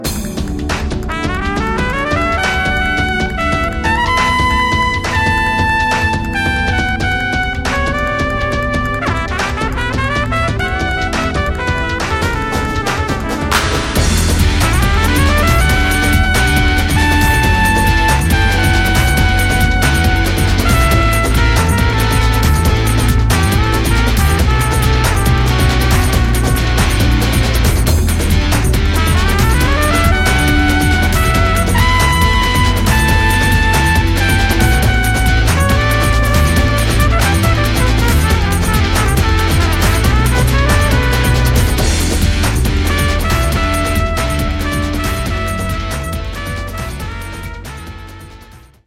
is the only sultry trumpet track in the entire score